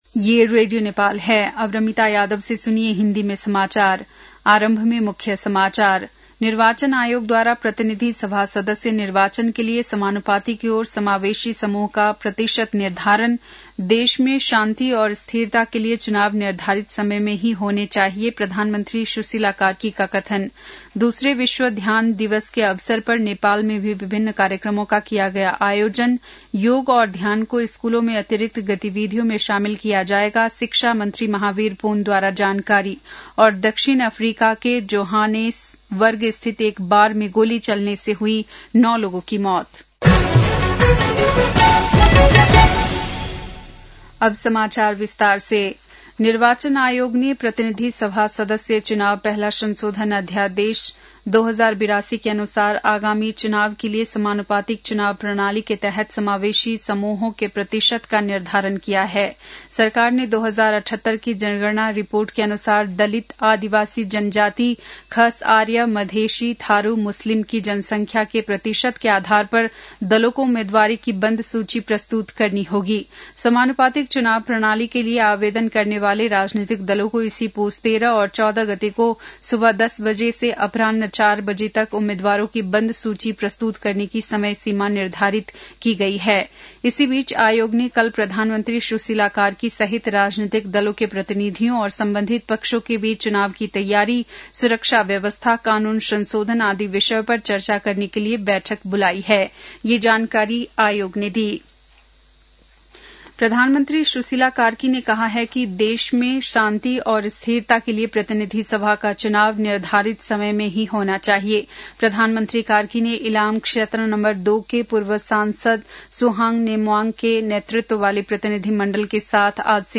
बेलुकी १० बजेको हिन्दी समाचार : ६ पुष , २०८२
10-pm-hindi-news-9-06.mp3